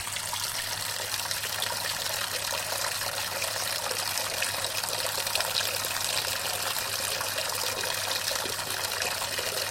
CAIDA DE AGUA
Tonos gratis para tu telefono – NUEVOS EFECTOS DE SONIDO DE AMBIENTE de CAIDA DE AGUA
Ambient sound effects
caida-de-agua.mp3